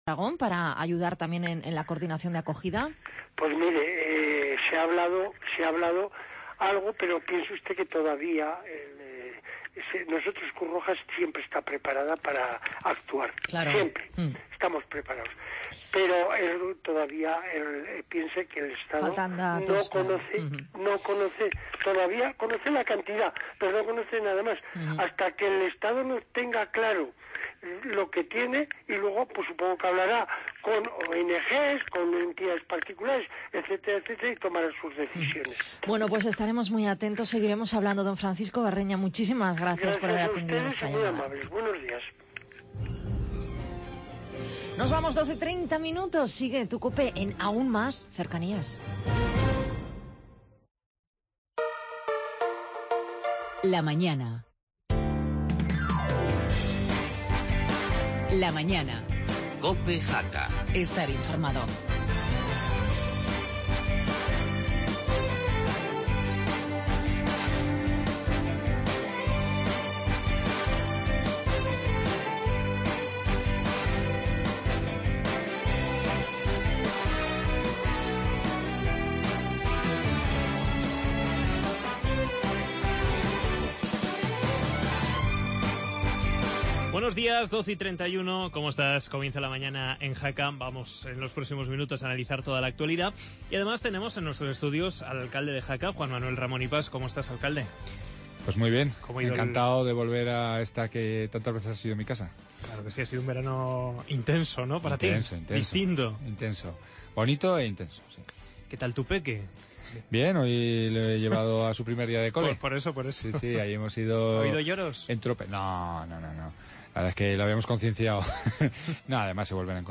AUDIO: Actualidad de la jornada y entrevista al alcalde de Jaca JM Ramón Ipas